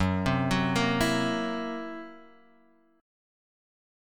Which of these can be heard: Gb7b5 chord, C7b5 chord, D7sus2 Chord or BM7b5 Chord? Gb7b5 chord